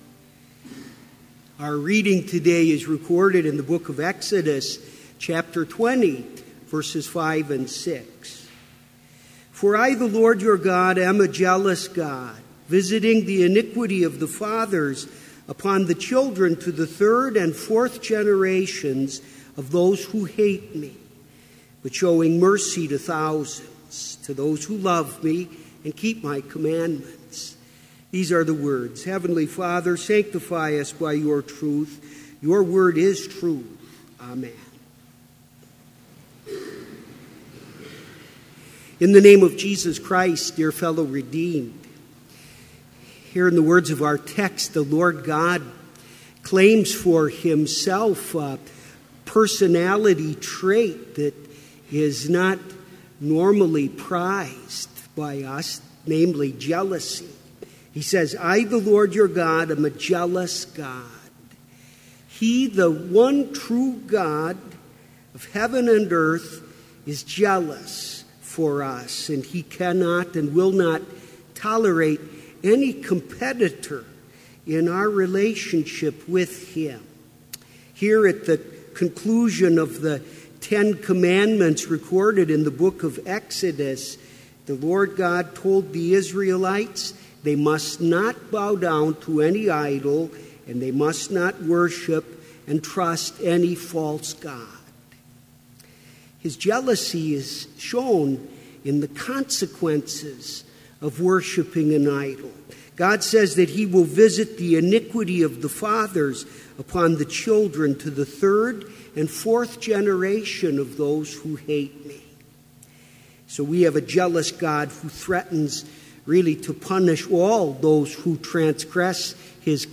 Sermon Only
This Chapel Service was held in Trinity Chapel at Bethany Lutheran College on Tuesday, September 27, 2016, at 10 a.m. Page and hymn numbers are from the Evangelical Lutheran Hymnary.